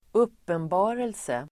Uttal: [²'up:enba:relse]